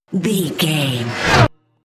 Riser electronic fast
Sound Effects
In-crescendo
Atonal
Fast
bouncy
bright
driving
futuristic
intense
tension
the trailer effect